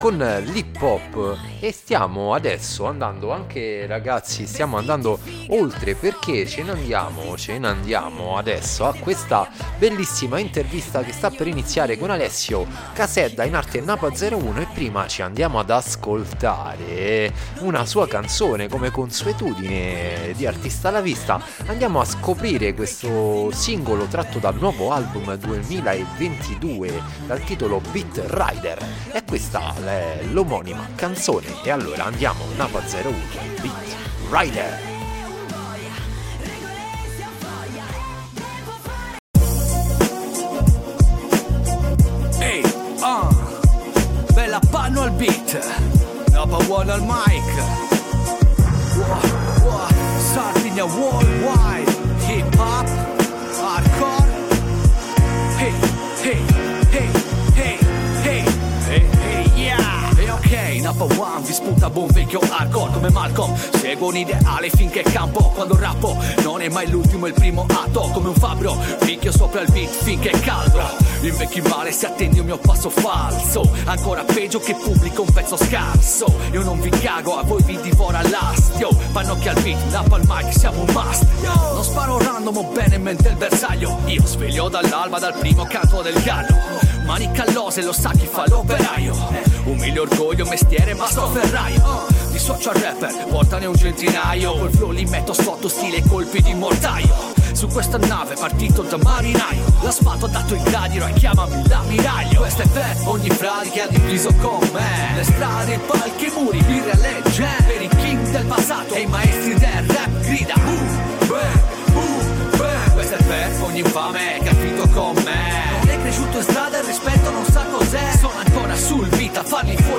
Abbiamo attraversato gli ultimi 20 anni del rap in Italia, parlato del suo nuovo album dal titolo Beatrider e aperto una finestra sull’approccio giovanile con questo stile. Una chiacchierata con molti spunti positivi e proiettata verso il futuro.